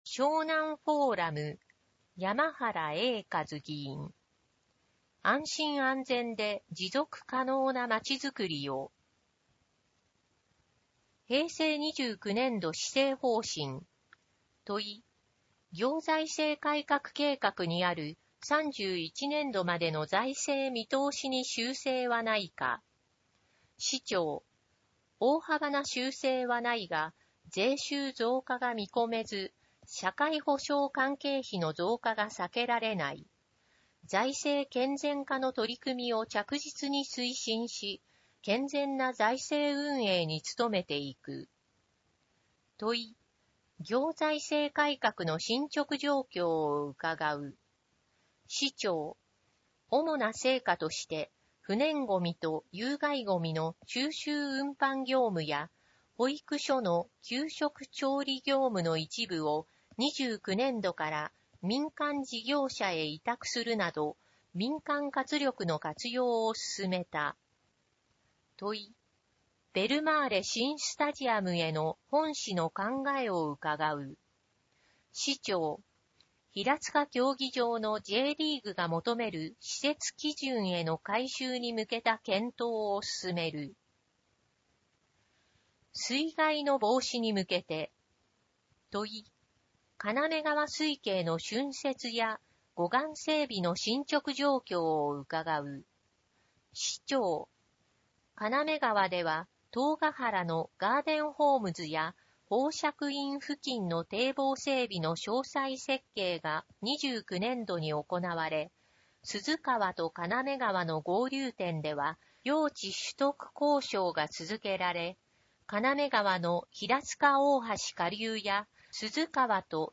平塚市議会では目の不自由な方に、ひらつか議会だよりを音声化した「声の議会だより」と、掲載記事を抜粋した「点字版議会だより」をご用意しています。
「声の議会だより」は平塚市社会福祉協議会と平塚音訳赤十字奉仕団の協力により作成しています。